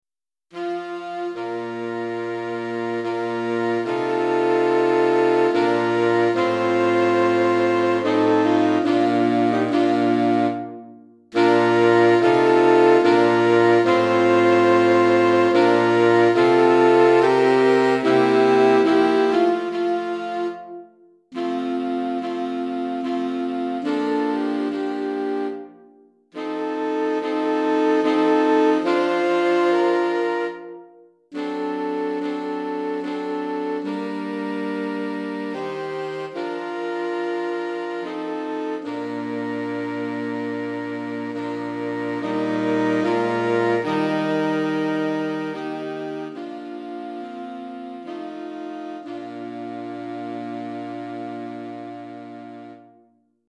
4 Saxophones (Soprano, Alto, Ténor, Baryton)